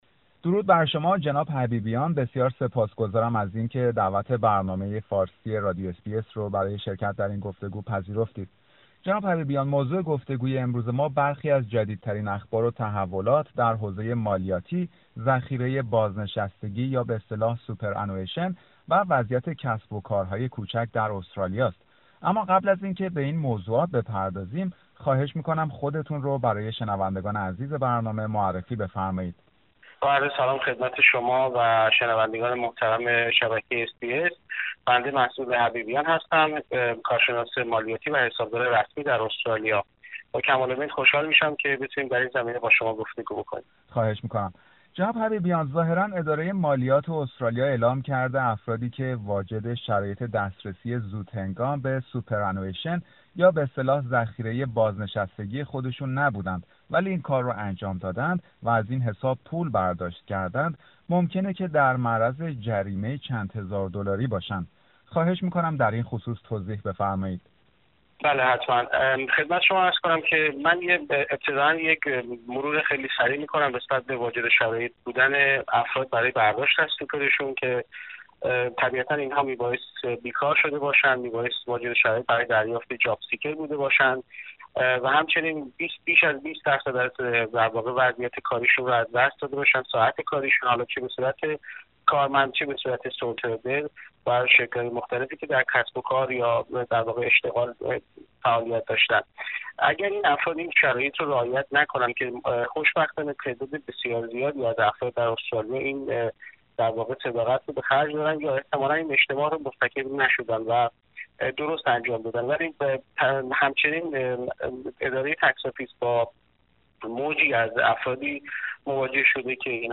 گفتگو با یک حسابدار درباره دسترسی به ذخیره بازنشستگی و فعالیت کسب و کارهای کوچک در دوران همه گیری